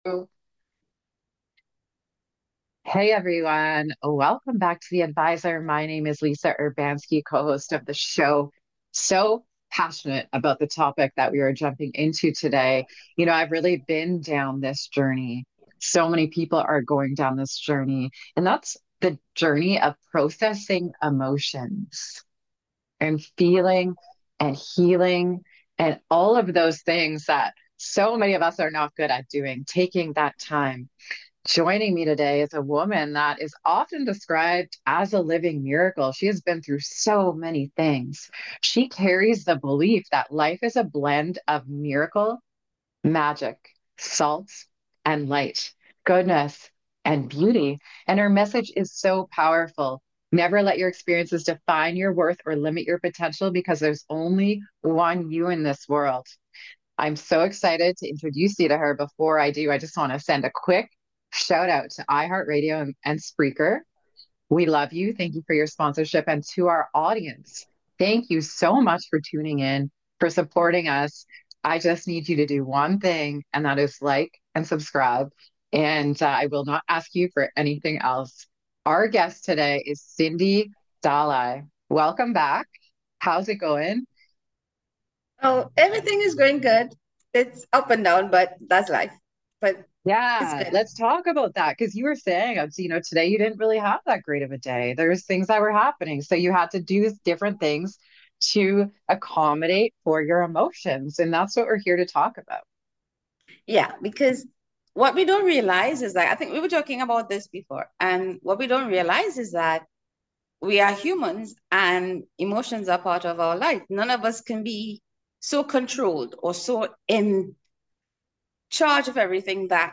You don't want to miss out on this captivating conversation that will ignite your soul and empower your emotional well-being!